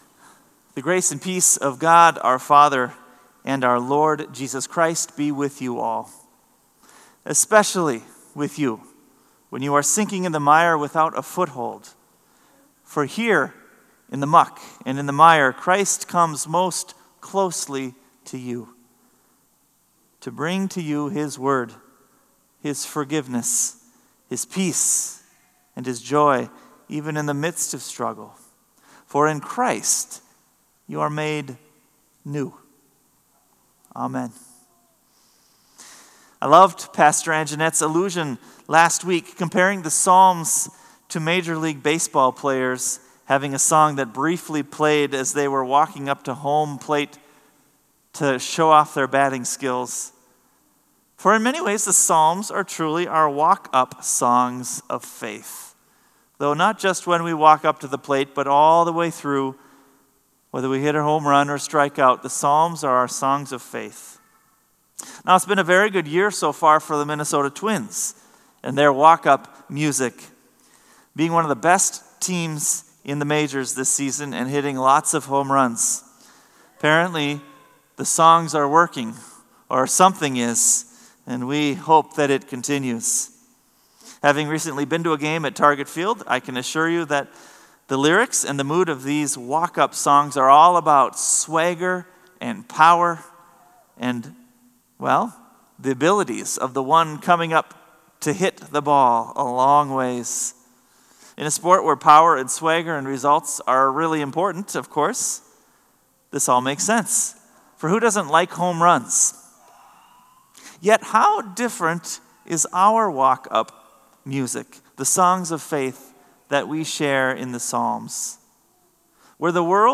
Sermon “Save Me O God!”